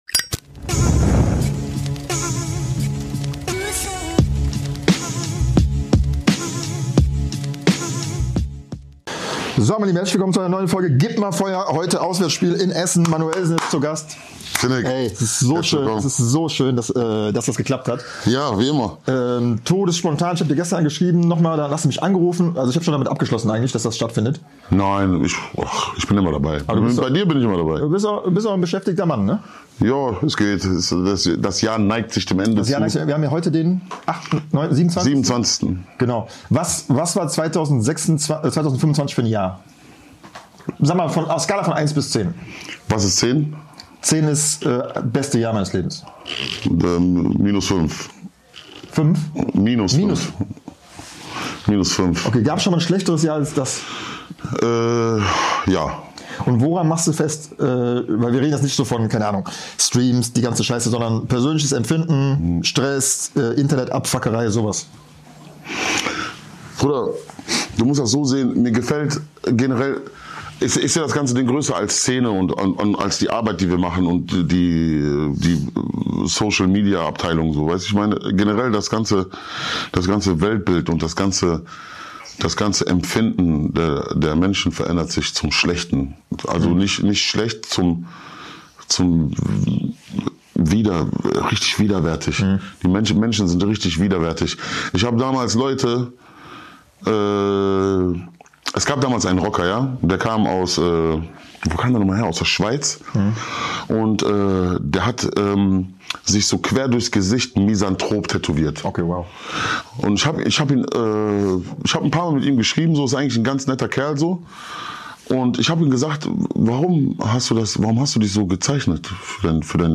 Ruhrpott-und Deutschrap-Legende Manuellsen war am Start und hat uns in seinem Homestudio in Essen empfangen. Es wurde viel philosophiert, sich aufgeregt und gelacht.